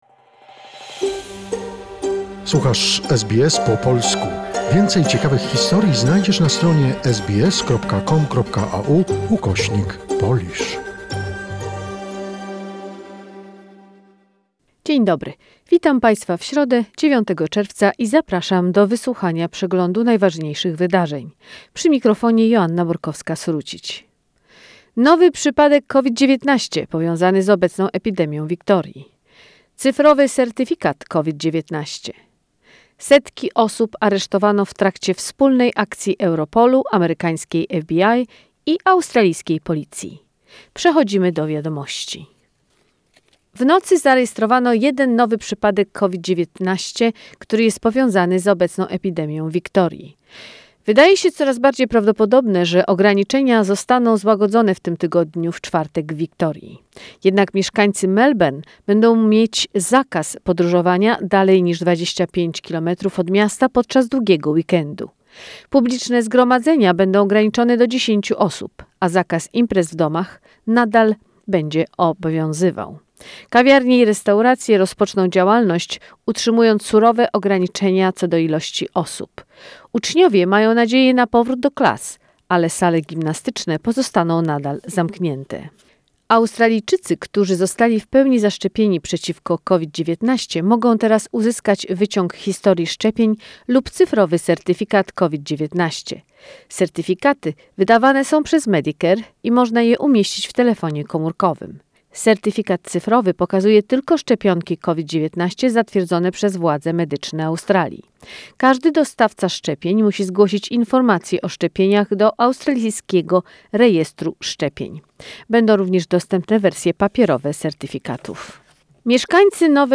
Skrót najważniejszych doniesień z Australii i ze świata, w opracowaniu polskiej redakcji SBS.